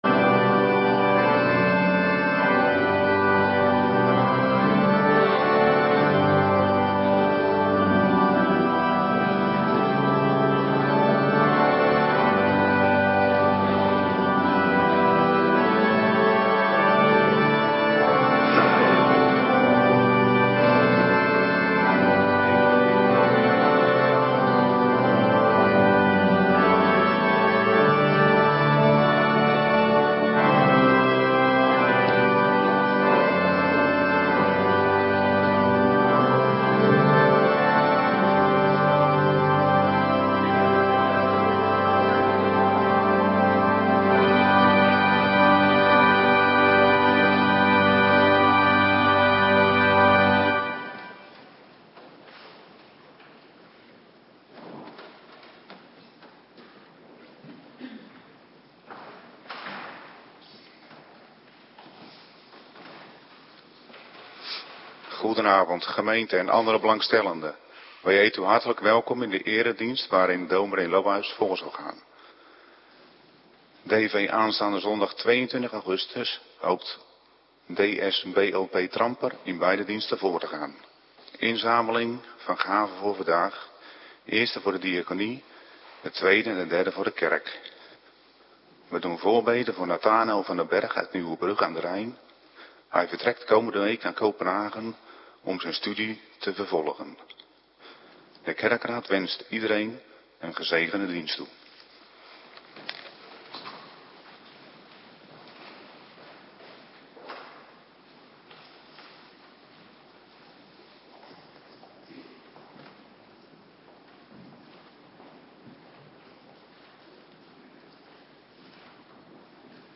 Avonddienst - Cluster B
Locatie: Hervormde Gemeente Waarder